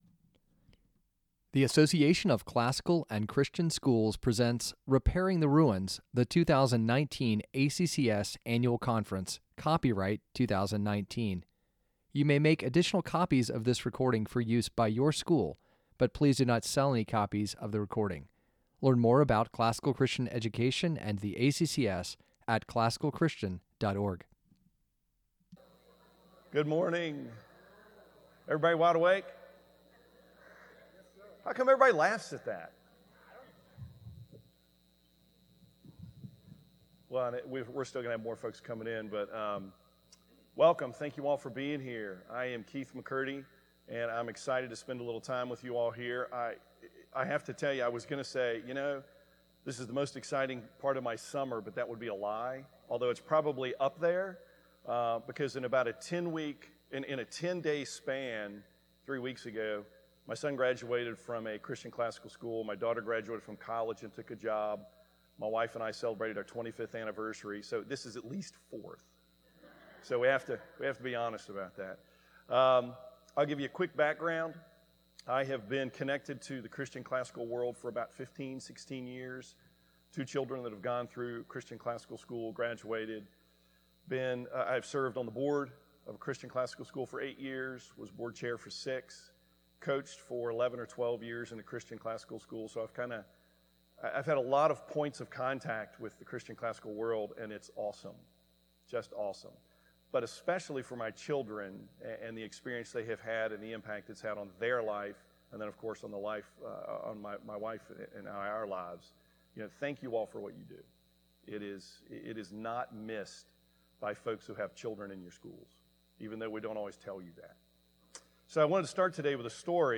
2019 Foundations Talk | 49:16 | All Grade Levels, Virtue, Character, Discipline